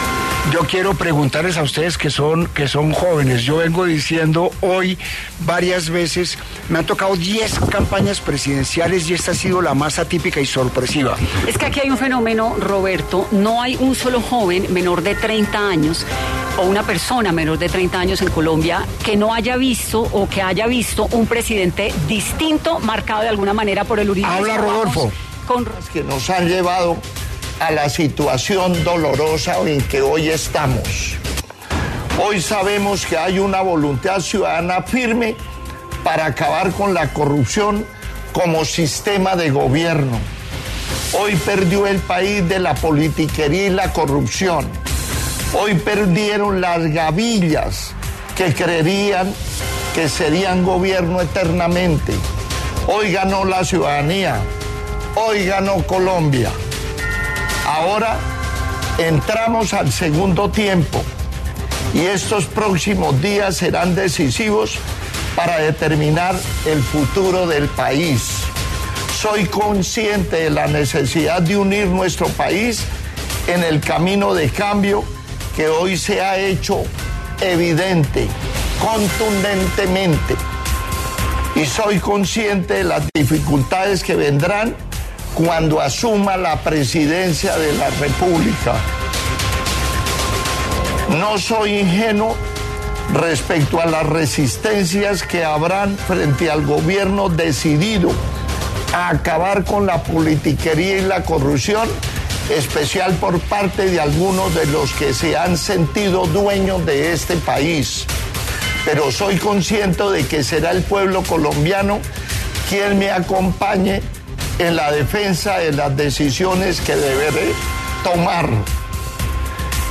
Discurso de Rodolfo Hernández sobre paso a segunda vuelta en elecciones 2022